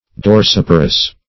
Search Result for " dorsiparous" : The Collaborative International Dictionary of English v.0.48: Dorsiparous \Dor*sip"a*rous\, a. [Dorsum + L. parere to bring forth.]
dorsiparous.mp3